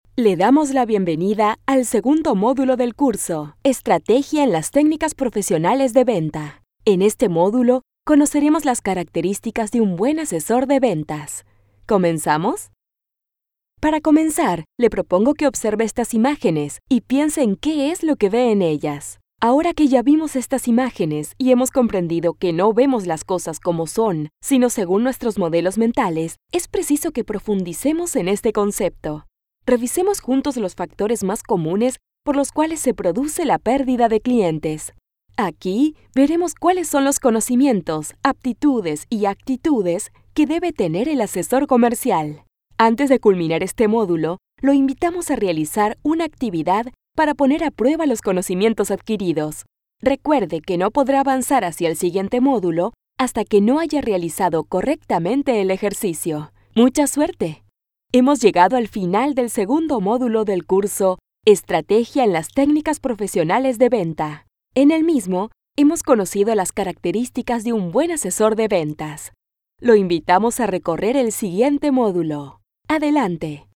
Female Voice Over, Dan Wachs Talent Agency.
Sincere, Conversational, Warm, Inspiring
eLearning